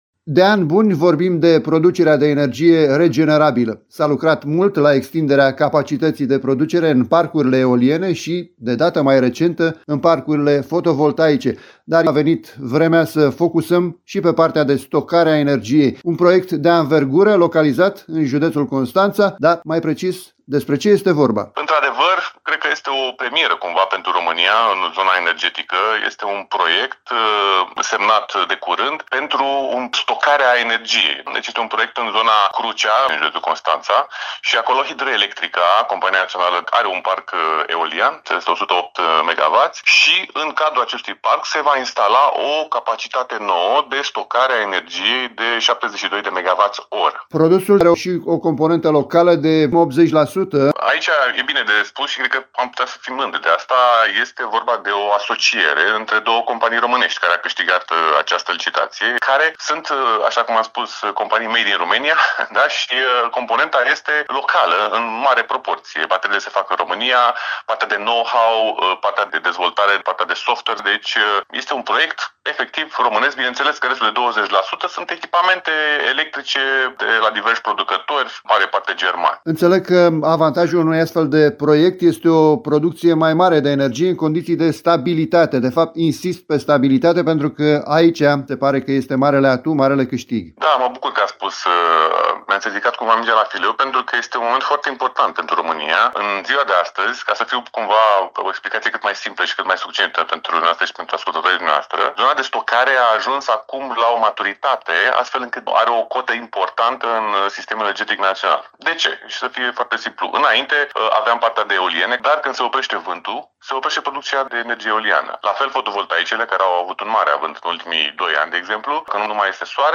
reprezentantul unei companii implicate în instalarea bateriilor.